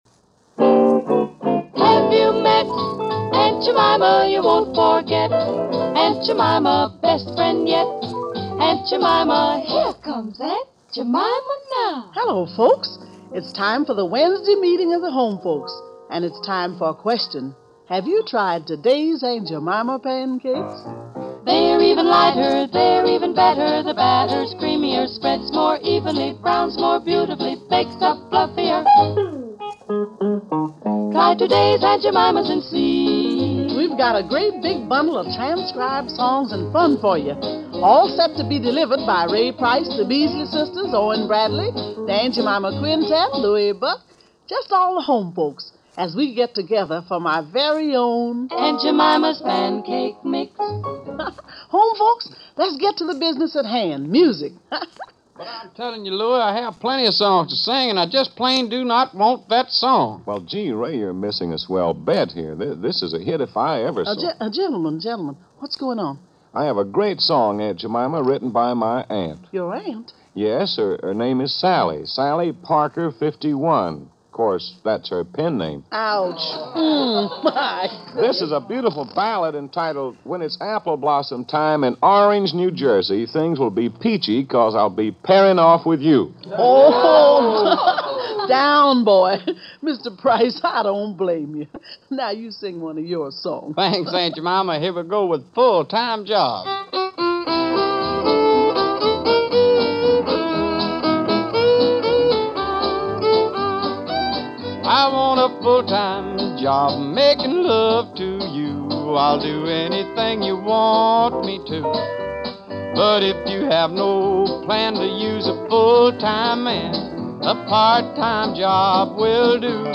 One of the best voices in Country Music.